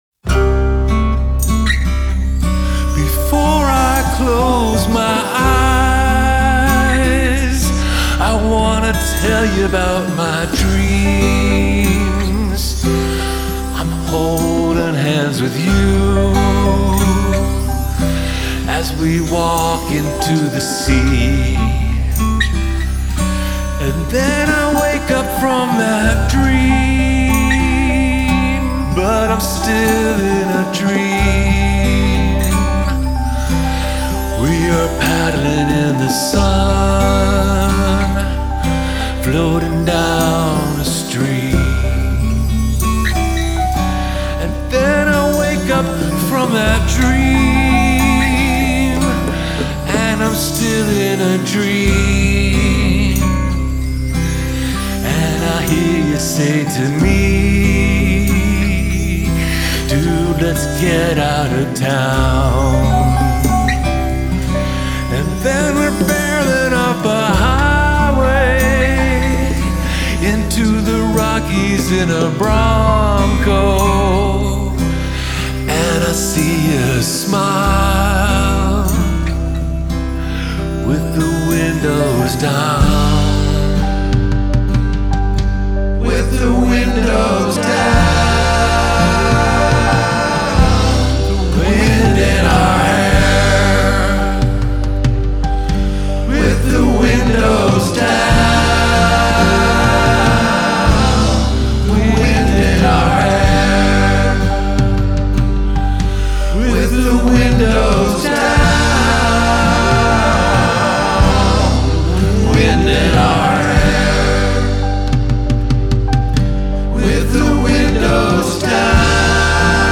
vocals, acoustic guitars [CGDGBE], piano
drums, percussion
double second pan
lead tenor pan
backing vocals